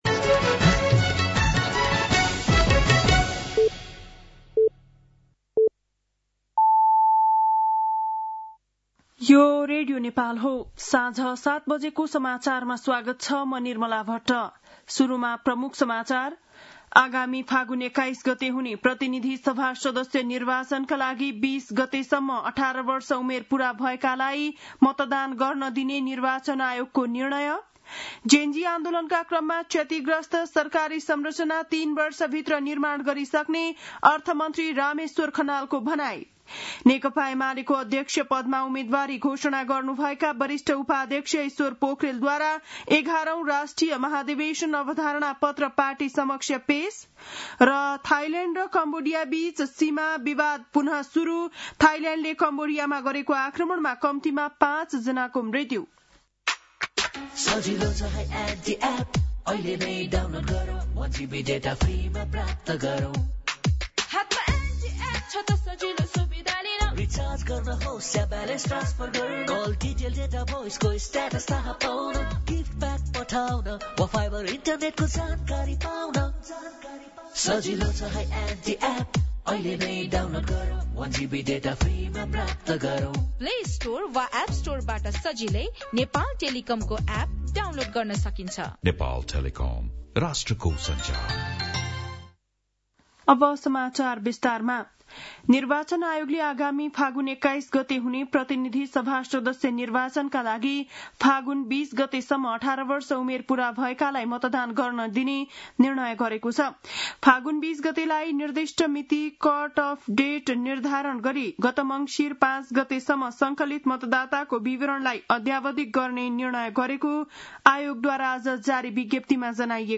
बेलुकी ७ बजेको नेपाली समाचार : २२ मंसिर , २०८२
7-pm-nepali-news-8-22.mp3